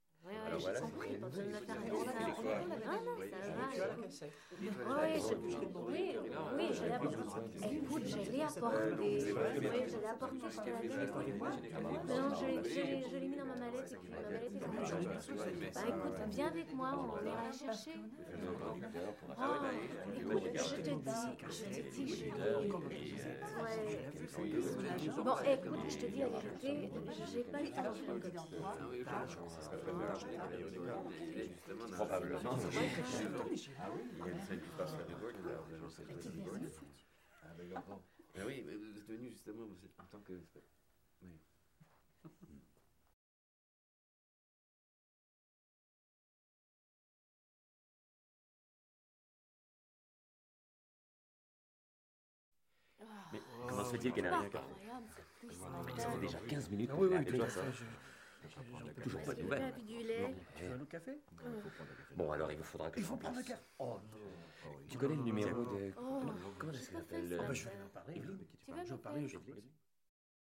描述：室内声乐（法国）氛围：媒体制作工作室
Tag: 工作室 定位资产 声乐氛围 室内